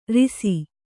♪ risi